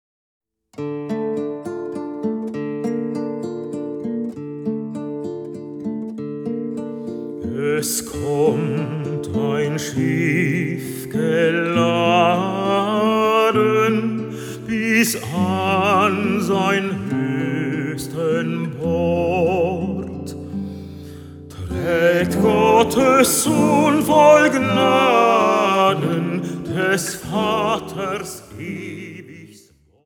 Vocal, Gitarre
Bariton
Jazz trifft klassischen Gesang